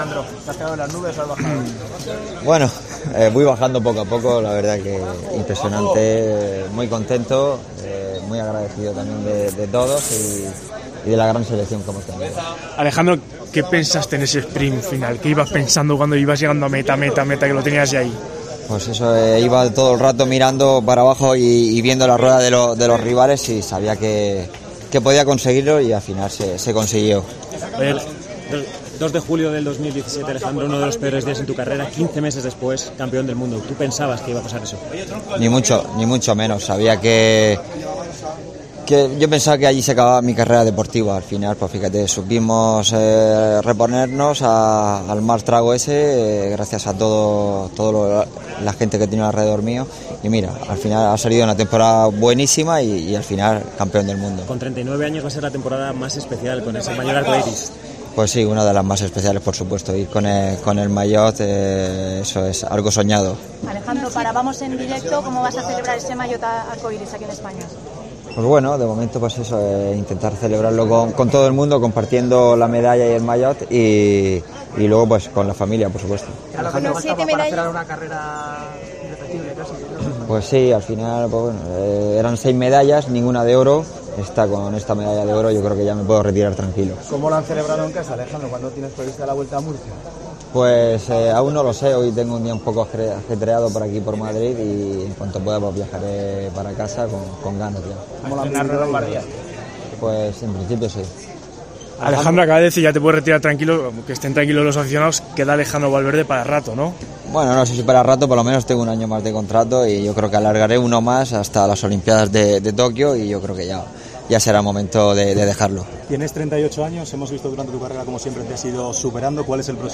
El ciclista del Movistar atiende a los medios a su llegada a Madrid tras conseguir el título mundial: "Voy bajando poco a poco de las nubes".
Junto a los periodistas que acudieron al aeropuerto a recibirle, Valverde aseguró que en aquella caída que sufrió en la primera etapa del Tour de Francia 2017 "pensaba que allí se acababa mi carrera deportiva".